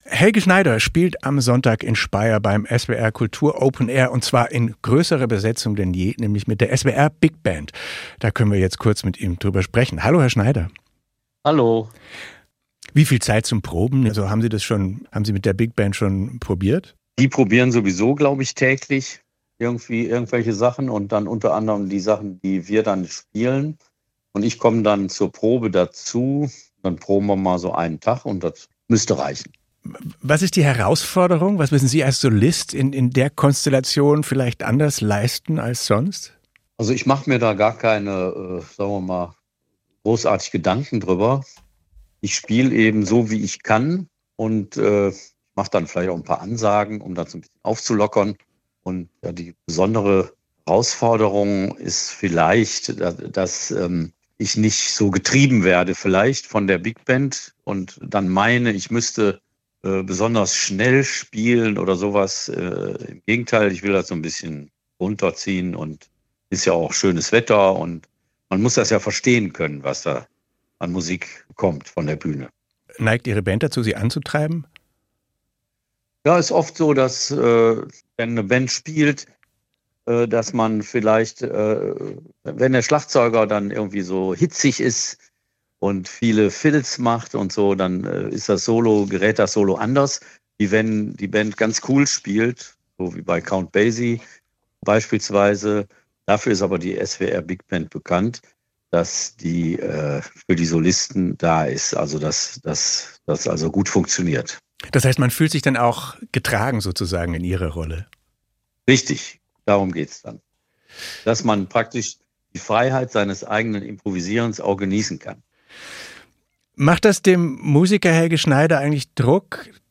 Im Interview spricht er über Big-Band-Jazz, Humor in Krisenzeiten und ein vergessenes Buch zur Erderwärmung.